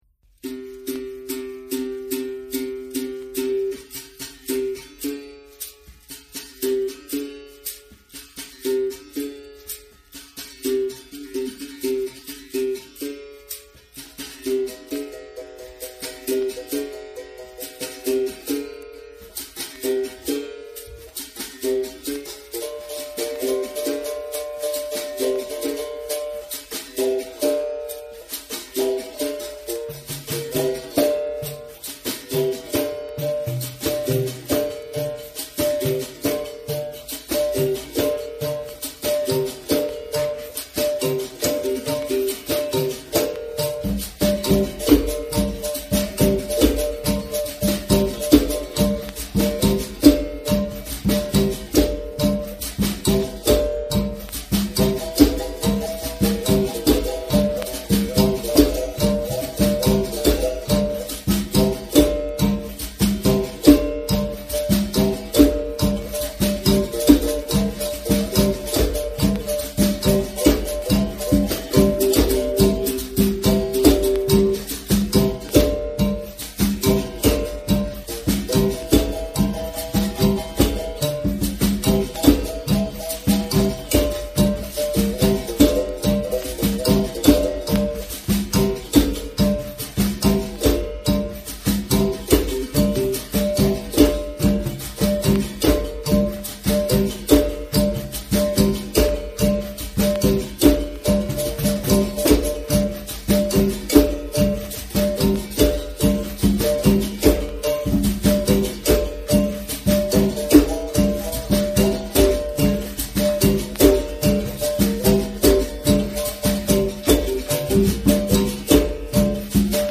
Berimbau Gunga
CH-CH-TOM-TIN-pause
Berimbau Médio
Berimbau Viola
Atabaque
ritmo-da-capoeira-de-angola_1_.mp3